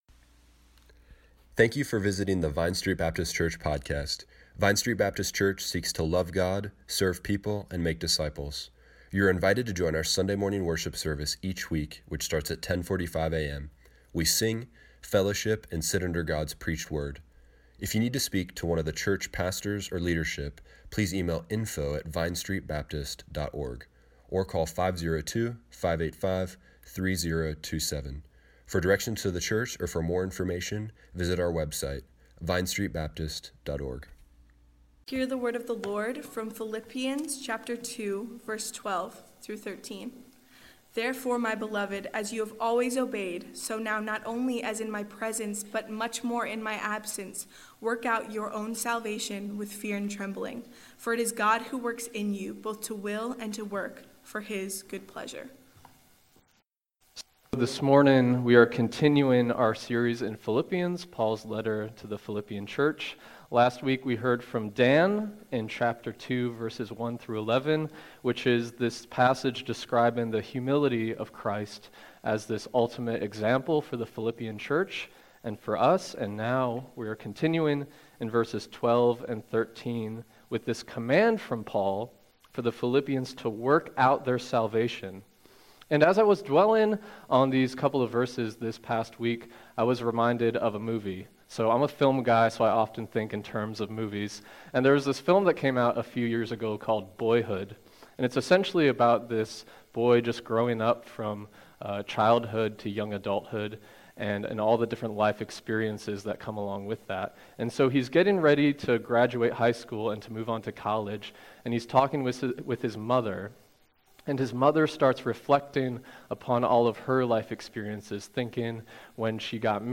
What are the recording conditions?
A Study in Unity Service Morning Worship Tweet Summary March 17, 2019 Questions of the text: The who? The when? The what? The how? The why? Click here to listen to the sermon online.